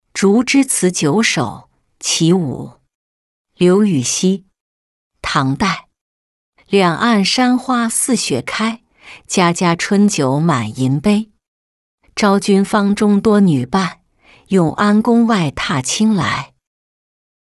竹枝词九首·其五-音频朗读